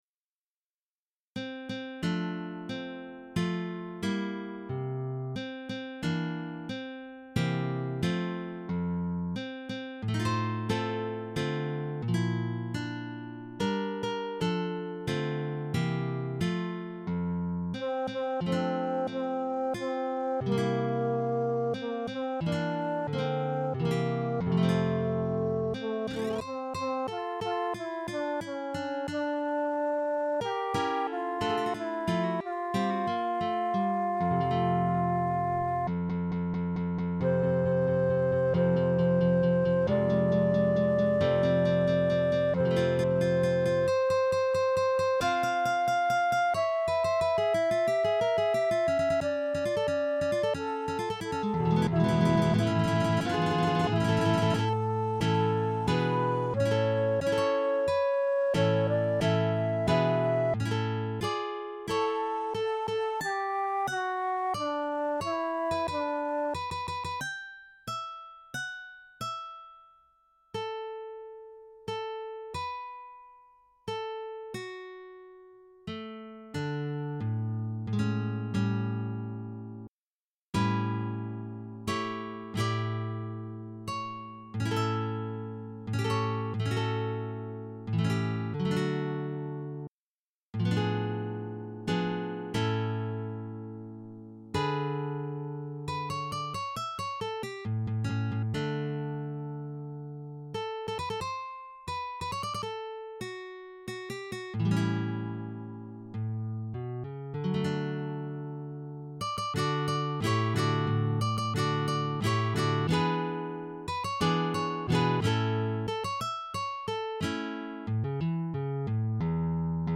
P/s.- Mi lasas ĉi tiun muzikan juvelon, komponita de mi mem, sur popola melodio, kiel omaĝon al estontaj datrevenoj de nia kara magazino,